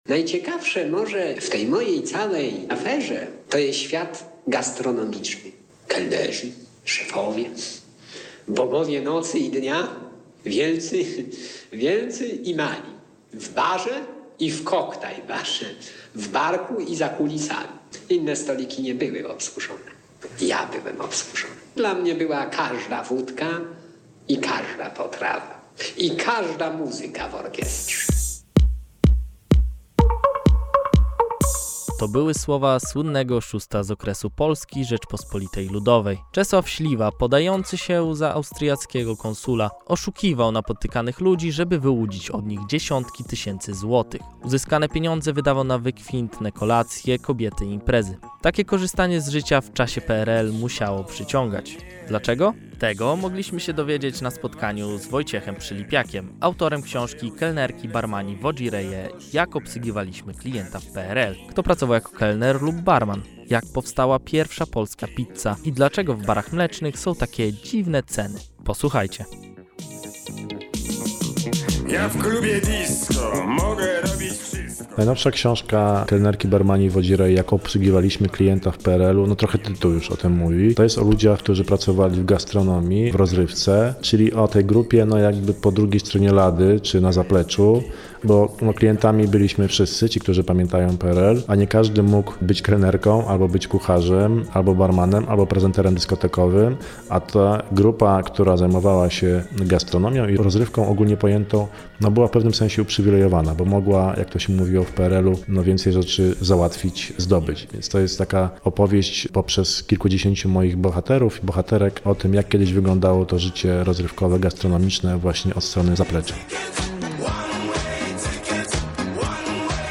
O tym mówił na spotkaniu w Planecie 11